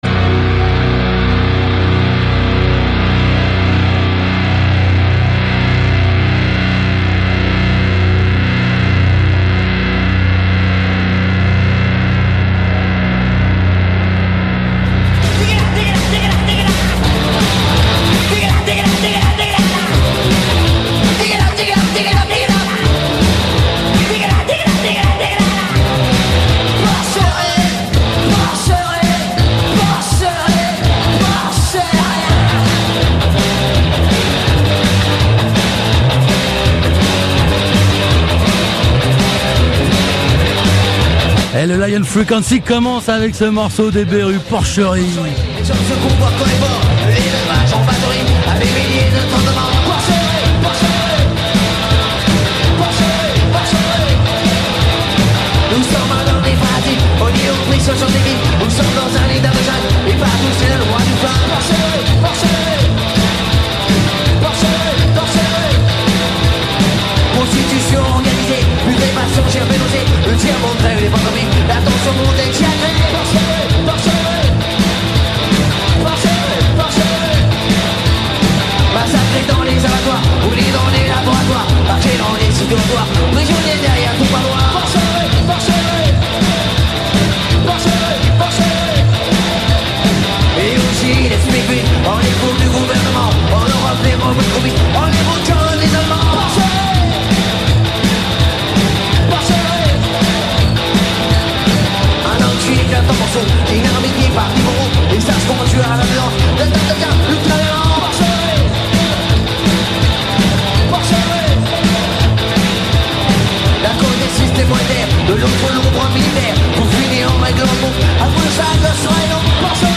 * Lion FreeCaency Radio Show *